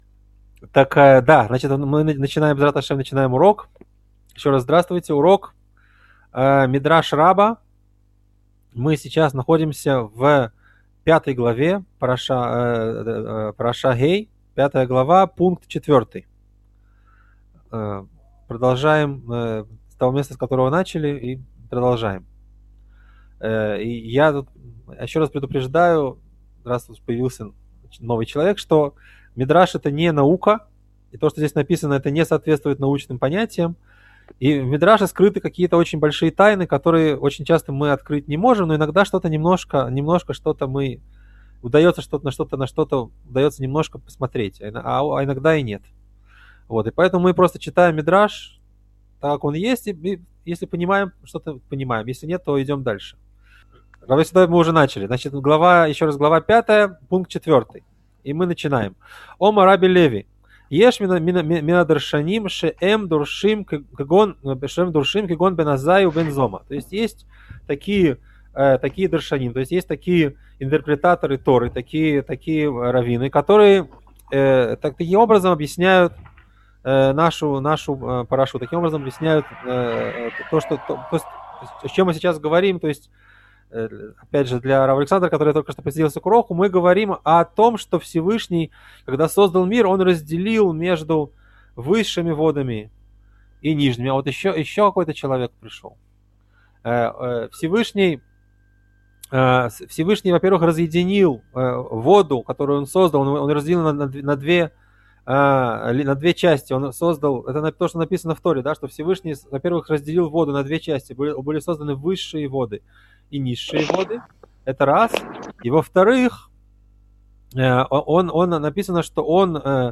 Урок 31. Что Бог приказал водам?